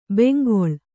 ♪ bengoḷ